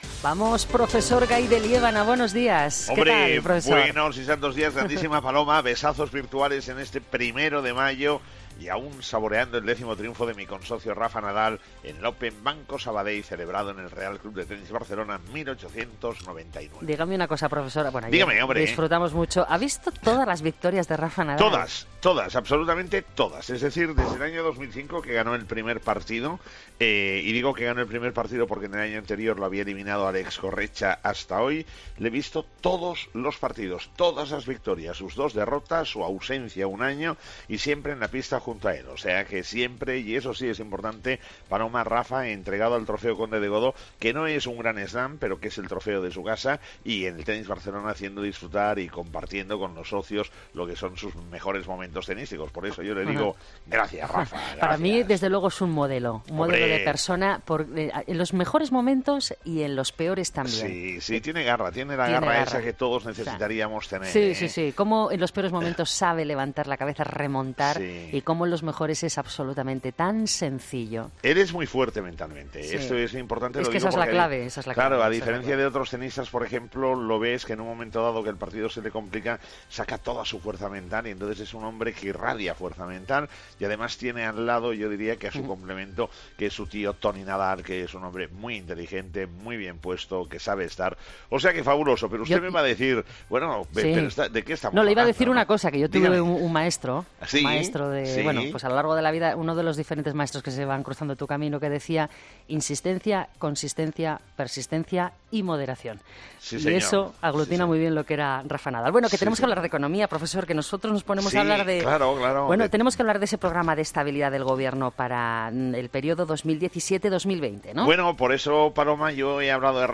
Todas las mañanas la actualidad económica con el profesor Gay de Liébana en 'Herrera en COPE'.